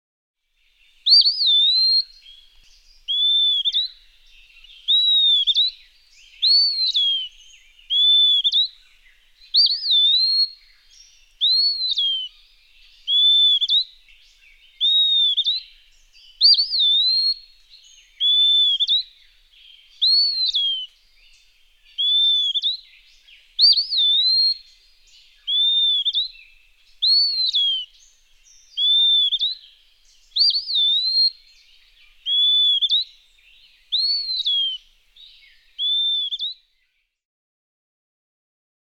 ♫608. Eastern wood-pewee: The blistering pace of dawn singing, now with a third song added, the ah-di-dee. Example 1. June 12, 2004. Quabbin Park, Ware, Massachusetts. (0:38)
608_Eastern_Wood-pewee.mp3